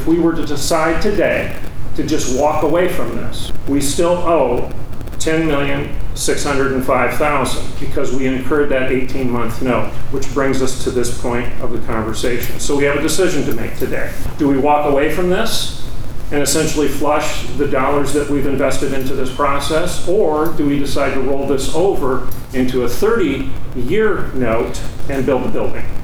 Before making that decision, District One Commissioner Everett Piper gave a presentation letting everybody